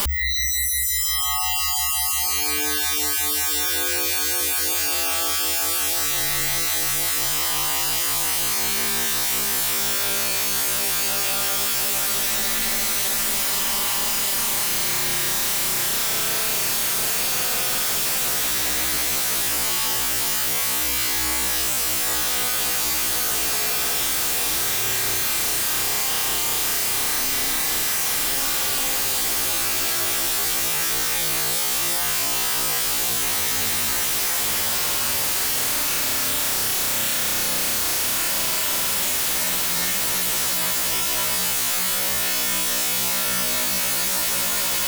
These audio recordings were recorded by other software while CodeSimian played audio on the speakers.
This *** fractal sound (download) *** is either of these equations, depending on what the sound quality is set to, 22khz or 44khz:
44 khz (recorded at this frequency long ago when this was the default):
fractalSound.wav